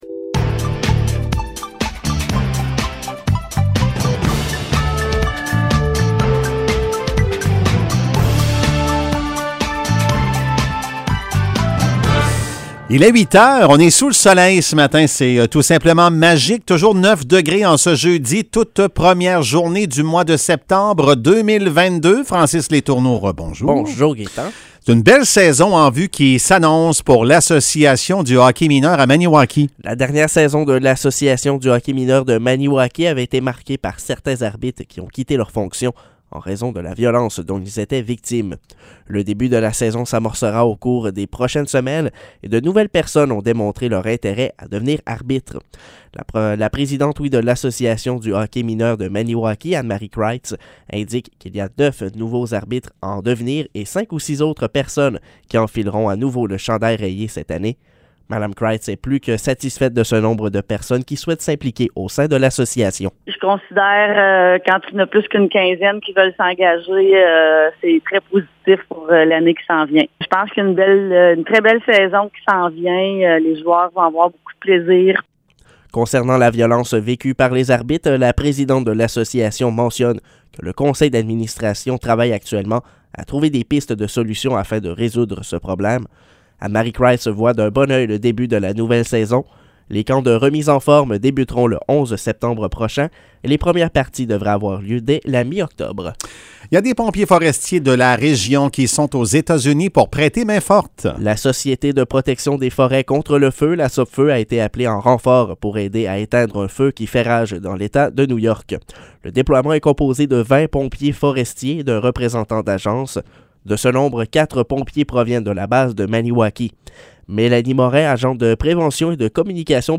Nouvelles locales - 1er septembre 2022 - 8 h